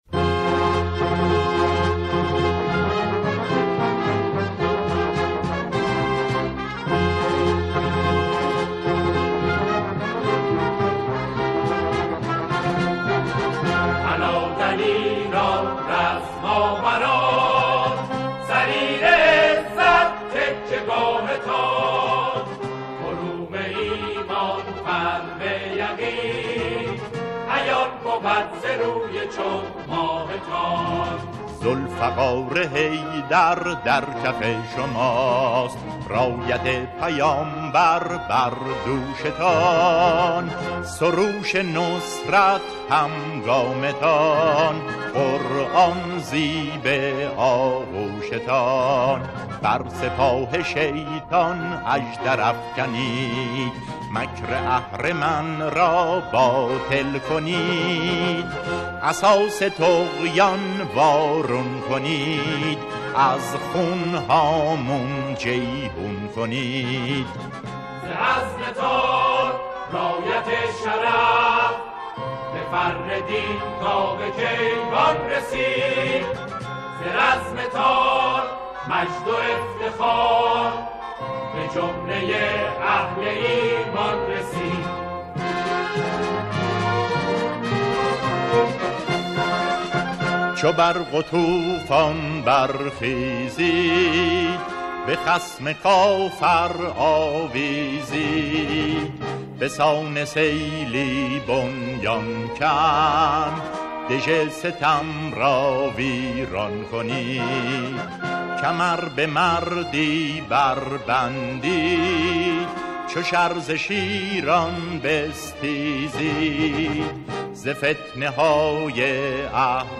سرود نوستالژی